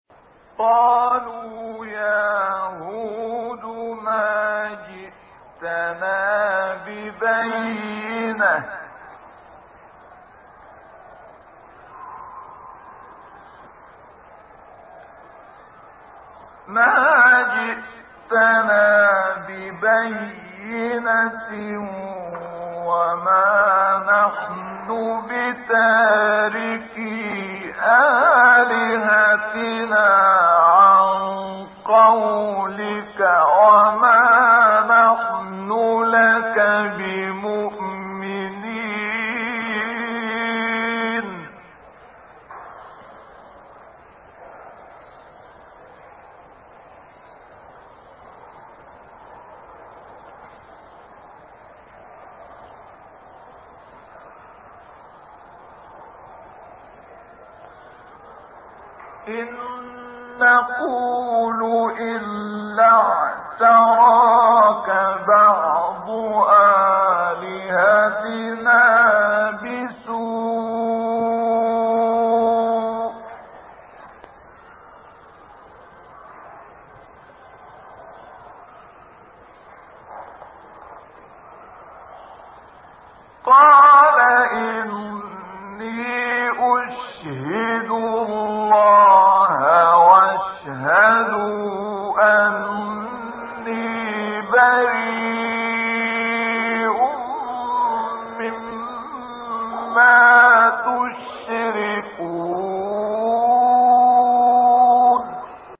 گروه شبکه اجتماعی: مقاطعی صوتی از تلاوت سوره هود با صوت عبدالفتاح شعشاعی که در مقام‌های مختلف اجرا شده است، ارائه می‌شود.
این فرازها از تلاوت سوره هود می‌باشد و در مقام های حجاز، نهاوند، رست، صبا، عجم، چهارگاه و سه‌گاه اجرا شده‌اند.
مقام صبا